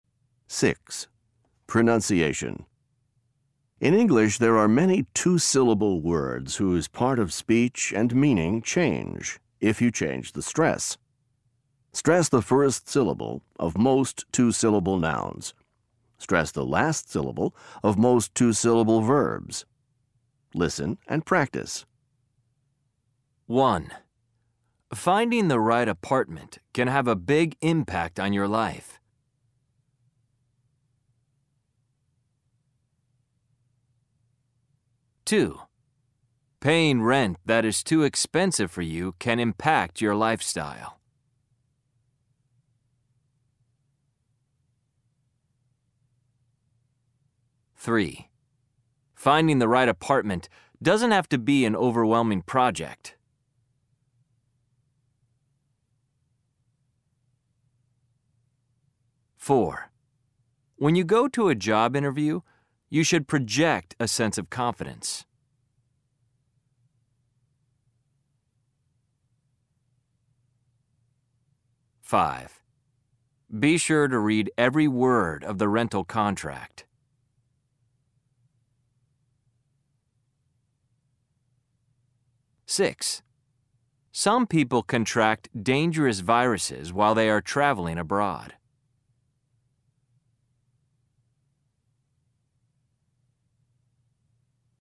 صوتيات للدروس 6 pronunciation mp3